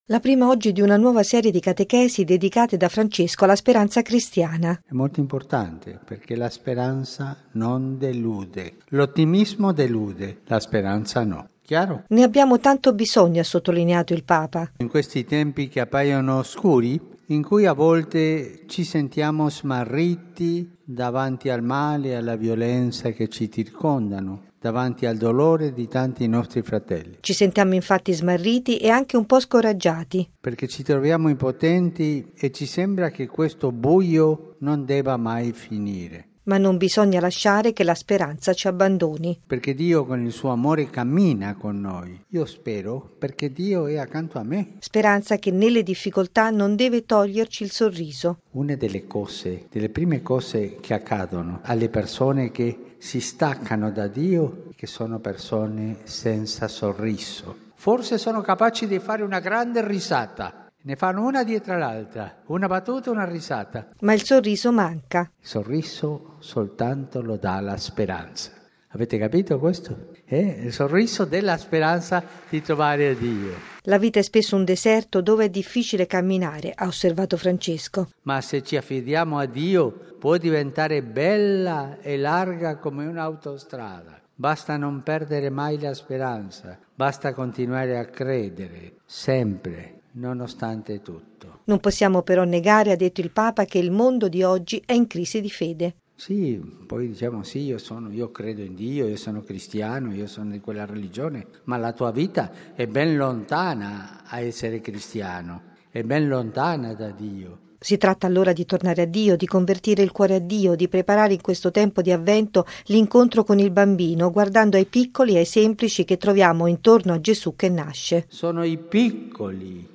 Bollettino Radiogiornale del 07/12/2016
La speranza cristiana non delude mai, lo ha sottolineato stamane il Papa all’udienza generale, rivolto ai fedeli di tutto il mondo convenuti nell’Aula Paolo VI in Vaticano, nel tempo di Avvento.